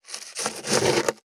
535台所,野菜切る,咀嚼音,ナイフ,調理音,まな板の上,料理,
効果音厨房/台所/レストラン/kitchen食器食材